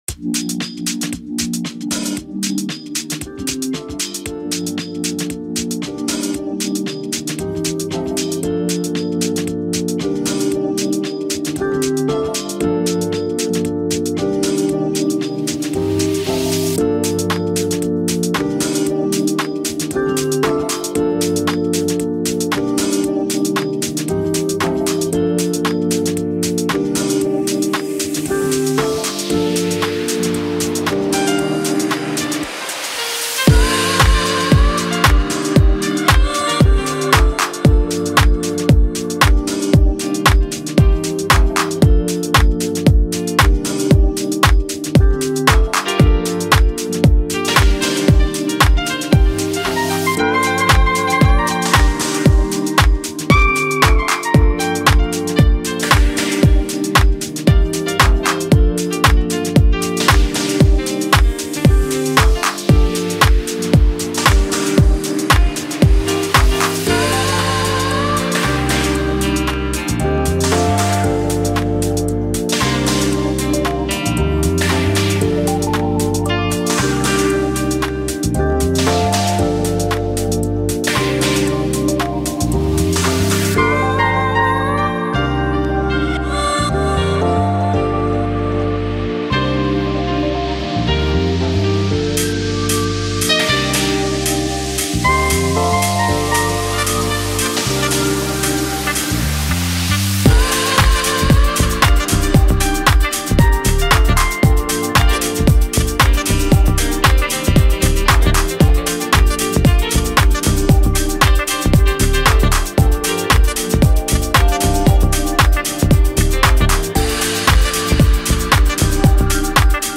Amapiano, Lekompo